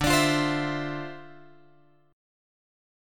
D Minor 9th